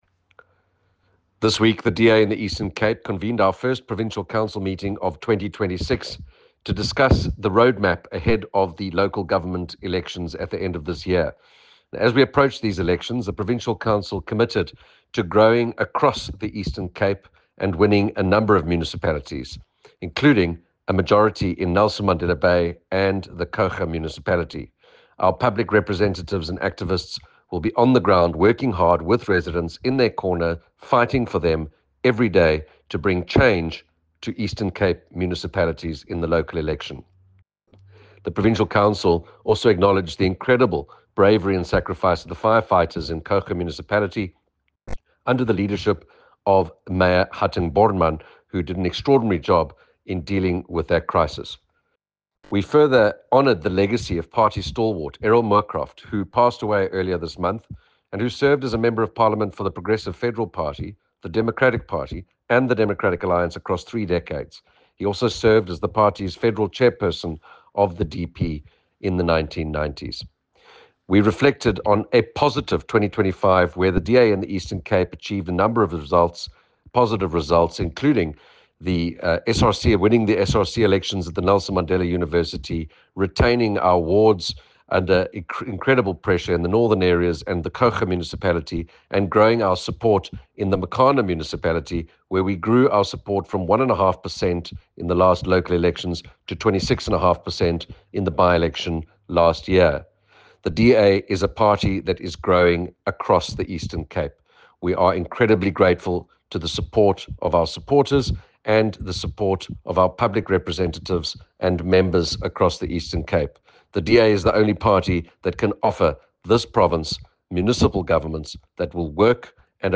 soundbite by Andrew Whitfield MP